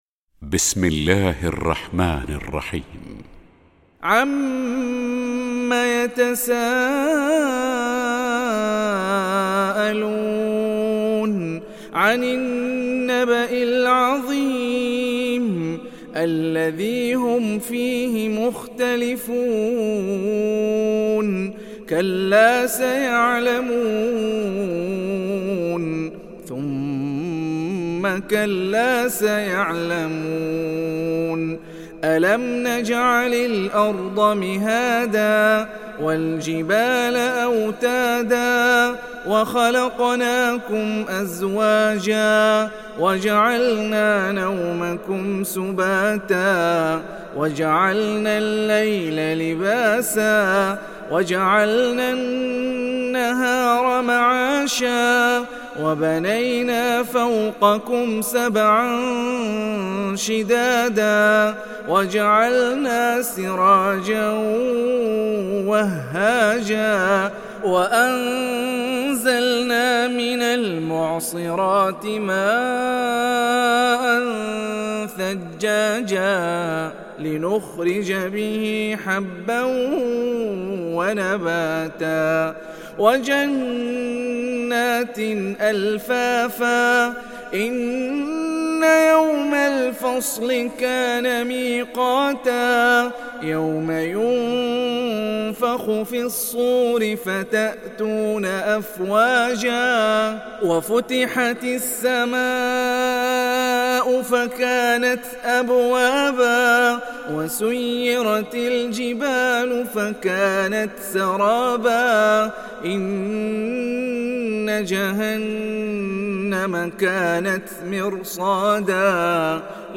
Surat An Naba mp3 Download Hani Rifai (Riwayat Hafs)